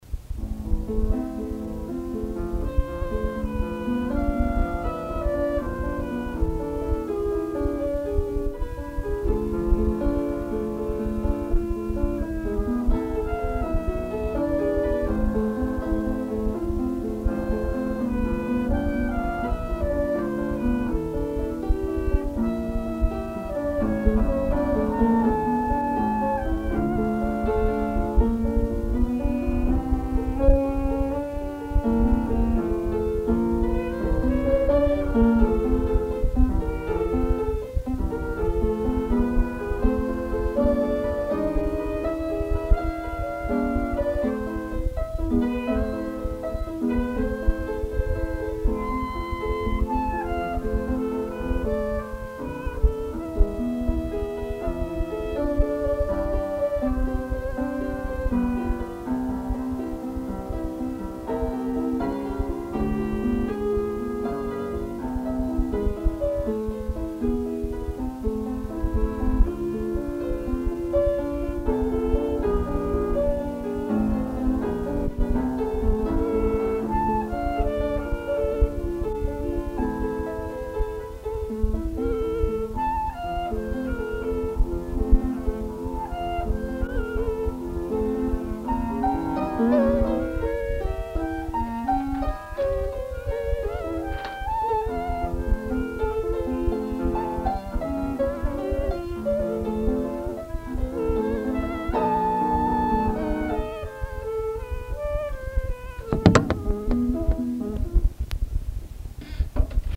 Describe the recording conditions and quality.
1971 (rehearsal)